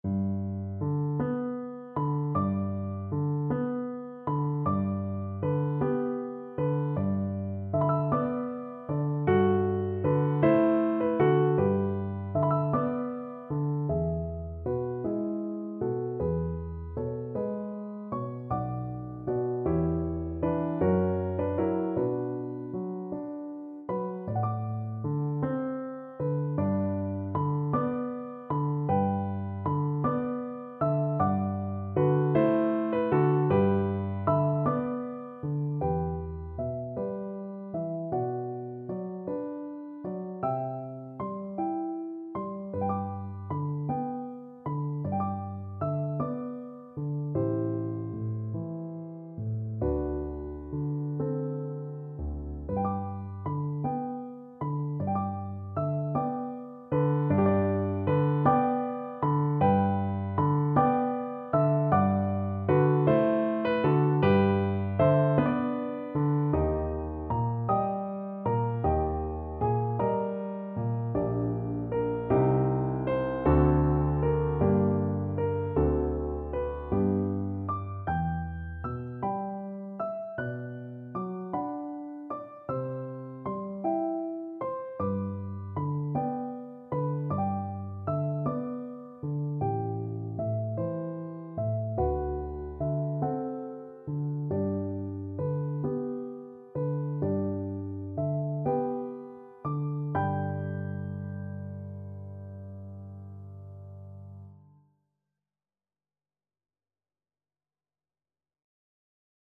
~. = 52 Allegretto
6/8 (View more 6/8 Music)